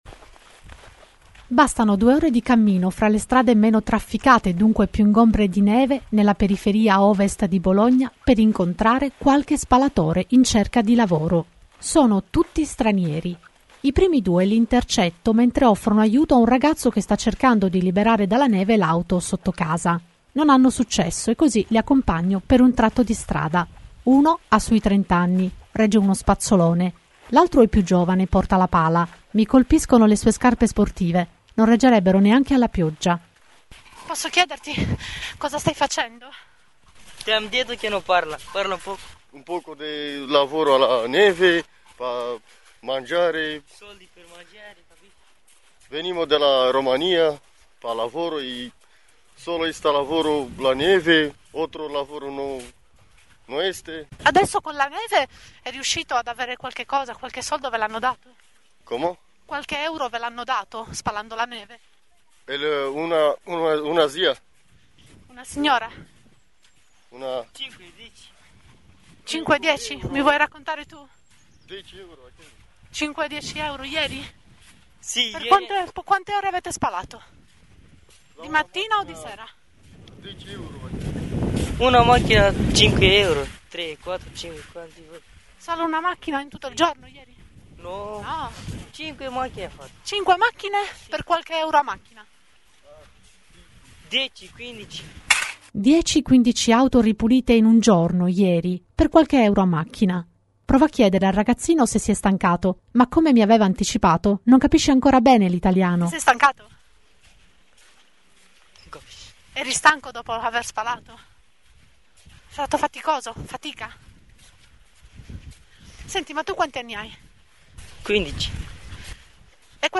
Bologna sotto la neve e le voci di chi prova a guadagnare qualche euro grazie alla super nevicata. Piccolo viaggio in una zona a caso della città, la periferia ovest.
Ecco il suo radioracconto